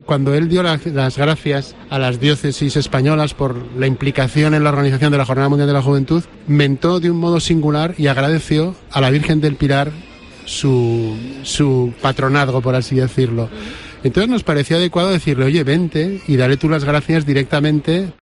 El arzobispo de Zaragoza, Monseñor Carlos Escribano, habla en COPE del cardenal Américo Aguiar.